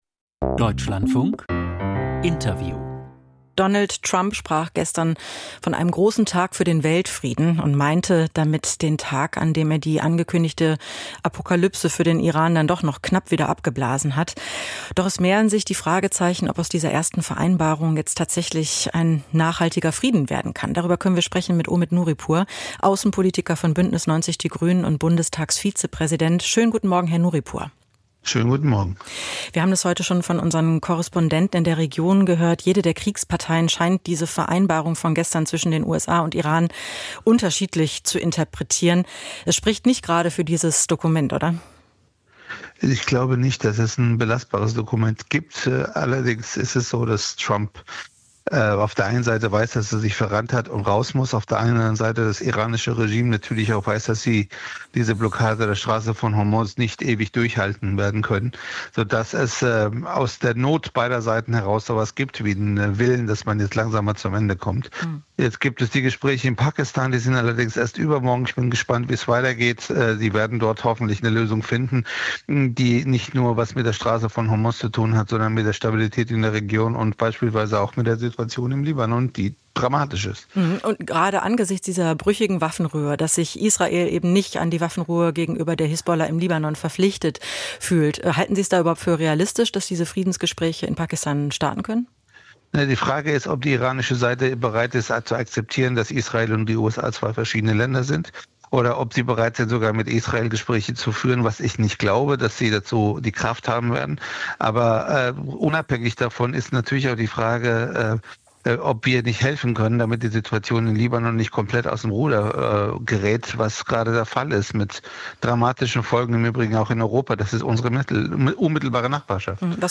Interview mit Omid Nouripour, B90/Grüne, Bundestagsvizepräsident, zum Iran